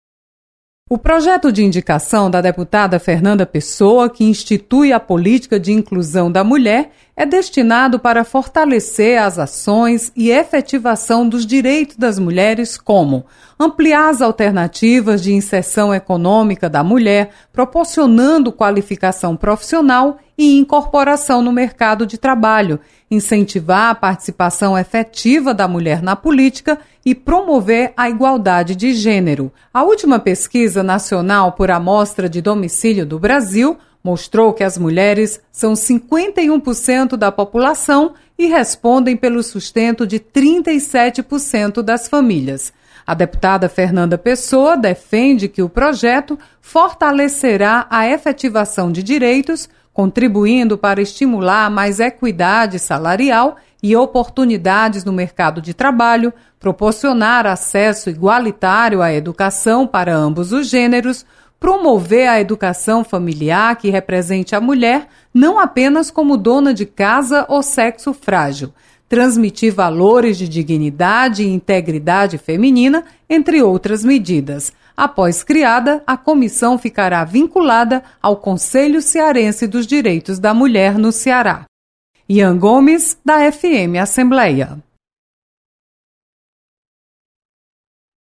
Você está aqui: Início Comunicação Rádio FM Assembleia Notícias Projeto